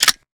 weapon_foley_drop_22.wav